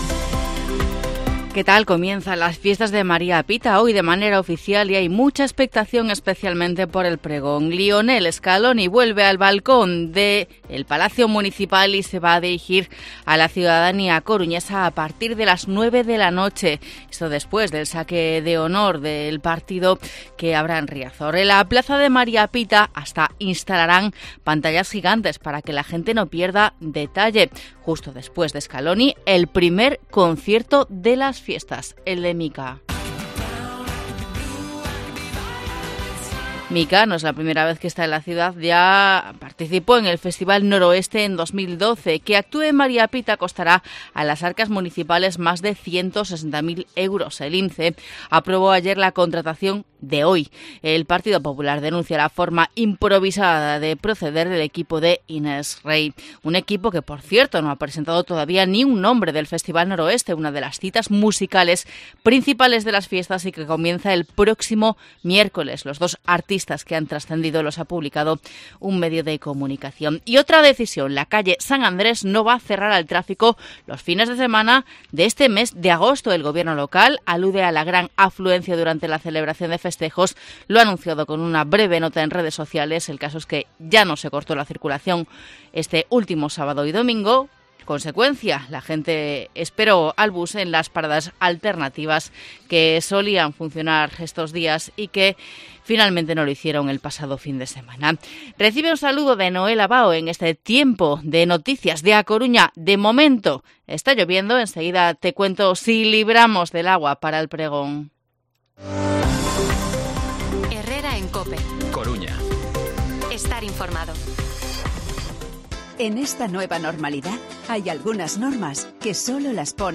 Informativo Herrera en COPE Coruña martes, 1 de agosto de 2023 8:24-8:29